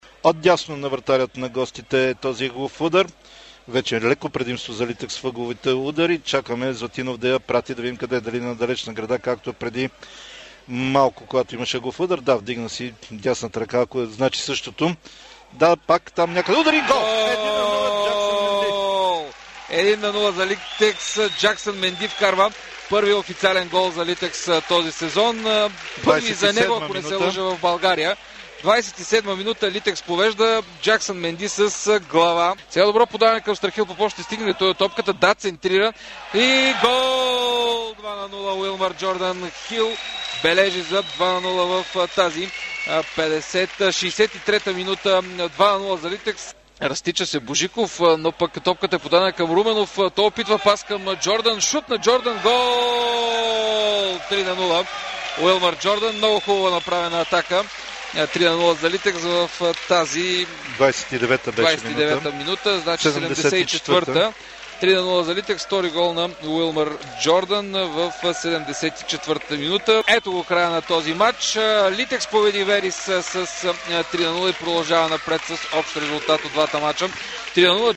Футболните коментатори на Дарик радио
Тук е мястото, където с кратка звукова картина ще бъдат представяни голове от мачовете на българските участници в европейските клубни турнири по футбол – засега за сезон 2014-2015.